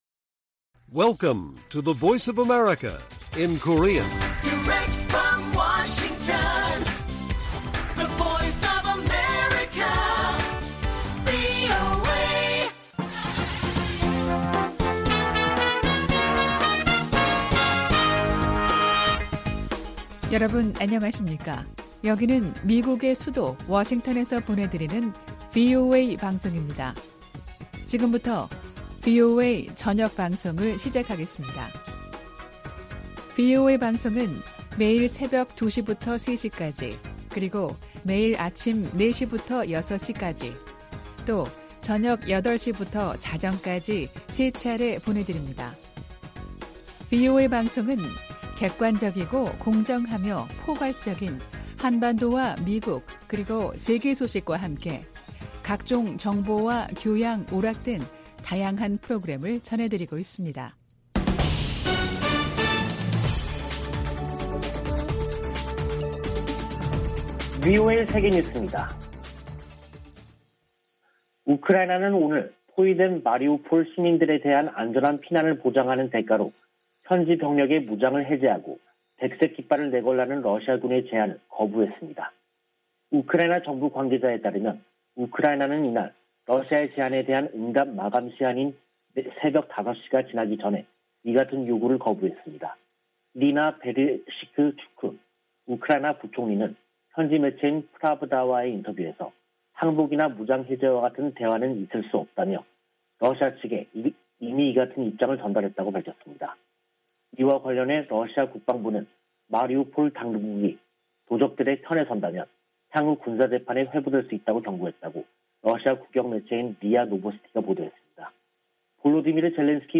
VOA 한국어 간판 뉴스 프로그램 '뉴스 투데이', 2022년 3월 21일 1부 방송입니다. 북한이 20일 한반도 서해상으로 방사포로 추정되는 단거리 발사체 4발을 쐈습니다. 미 국무부는 북한이 미사일 도발 수위를 높이는데 대해 모든 필요한 조치를 취할 것이라며 본토와 동맹국 방어 의지를 재확인했습니다.